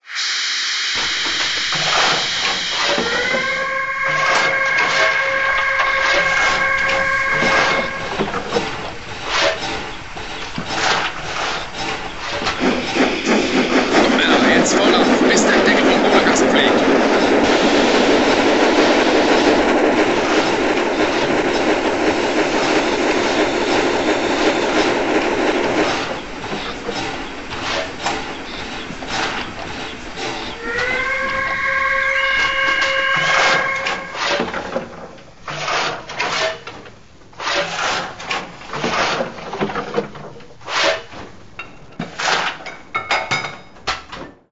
• Spielewelt-Decoder mfx+ mit umfangreichen Betriebs- und Geräuschfunktionen